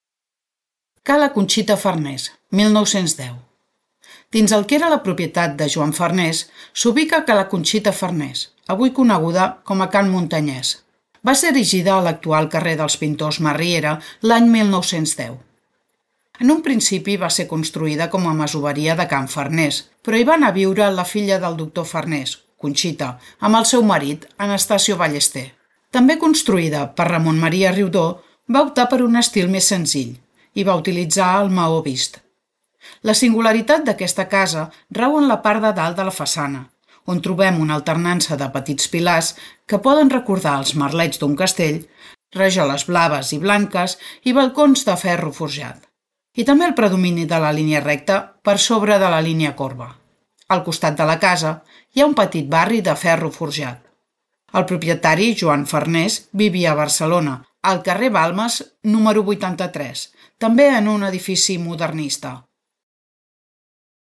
Ruta Modernista audioguiada